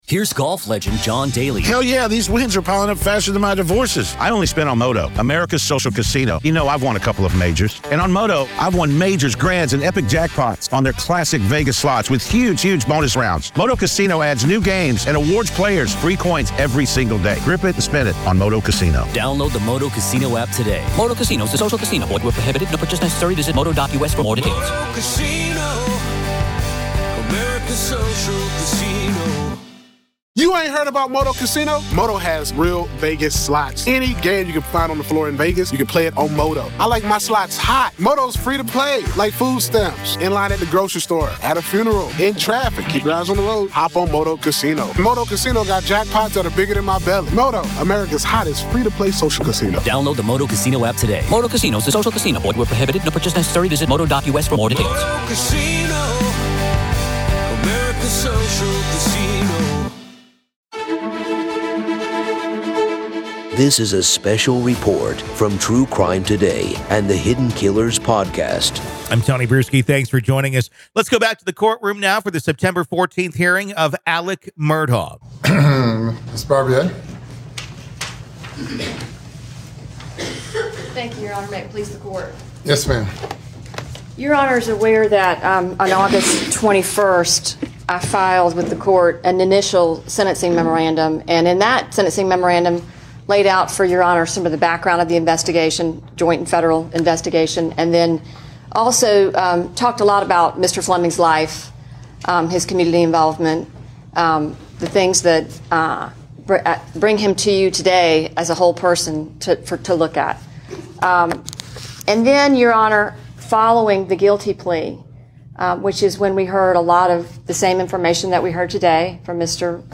Join us as we delve into raw audio excerpts from the September 14th hearing of Alex Murdaugh.
This episode takes you straight into the heart of the courtroom, offering an unfiltered and immediate perspective of the proceedings. Hear firsthand the prosecution’s arguments, Murdaugh's defense, and the reactions of those present.